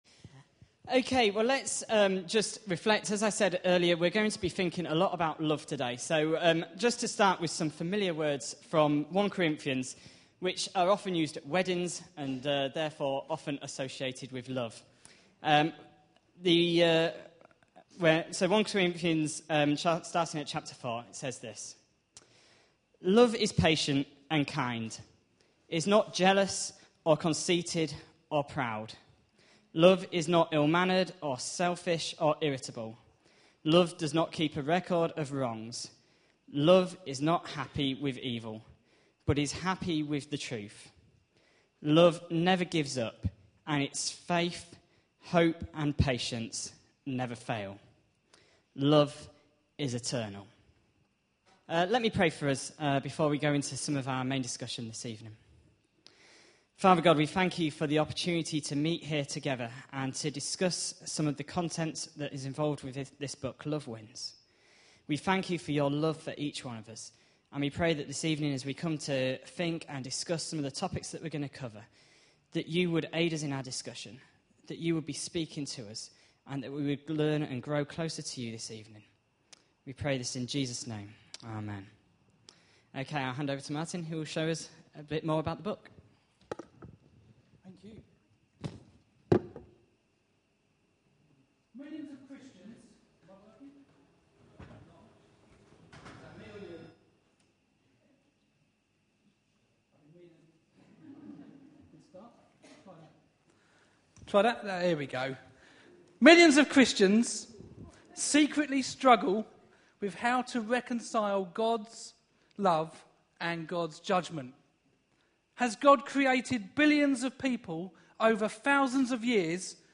A sermon preached on 22nd January, 2012, as part of our Looking For Love (6pm Series) series.